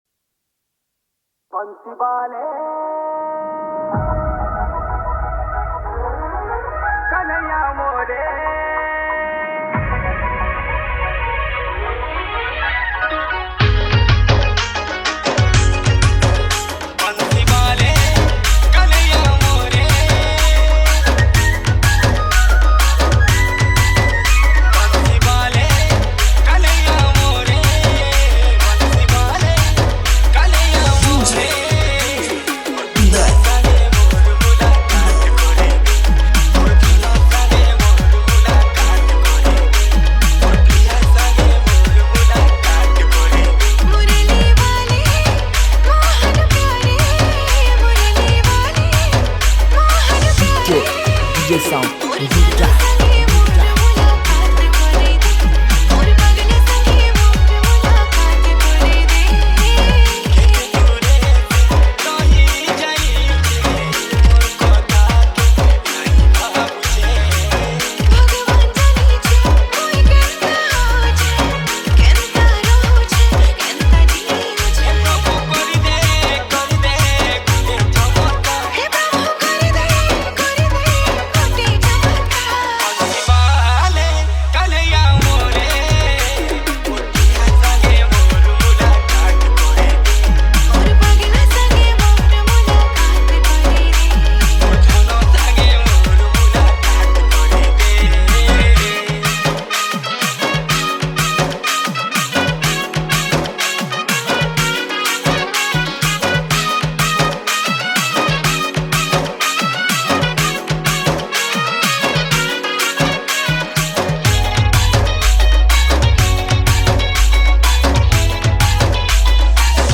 Sambalpuri Dj Song 2024